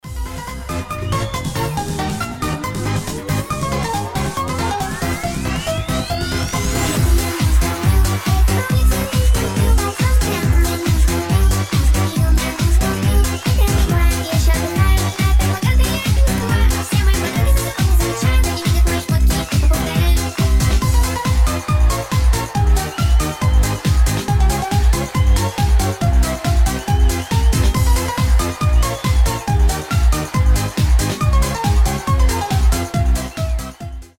• Качество: 320, Stereo
Mashup
ремиксы